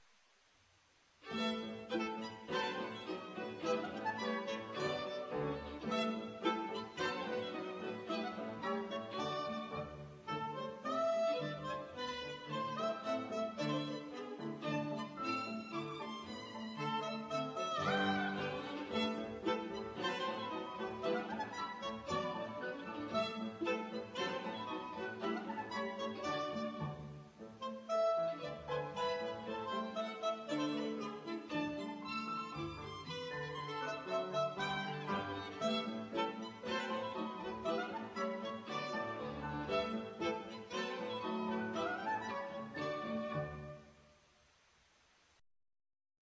Le dimanche 3 novembre 2002 à 17h, Conservatoire de Musique de Genève - Place-Neuve
Piano, clarinette, violon, violoncelle
2. Danse ukrainienne